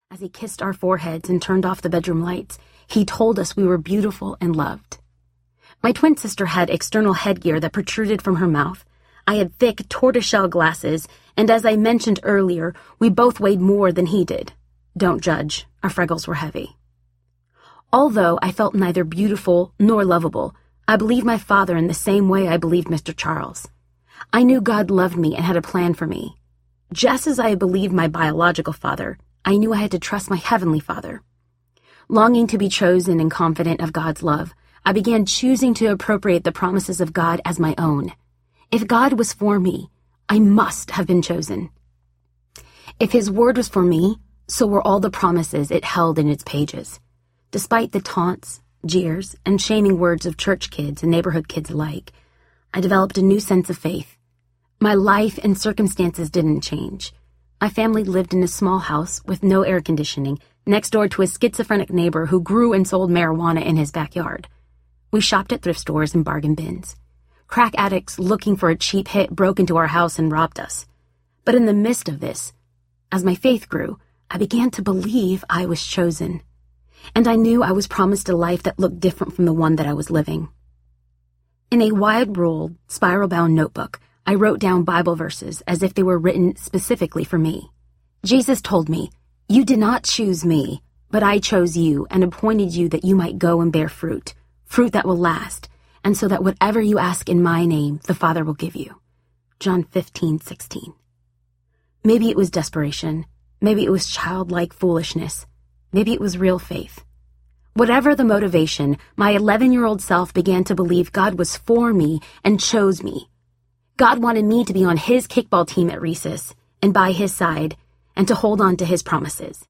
Play With Fire Audiobook
4.15 Hrs. – Unabridged